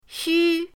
xu1.mp3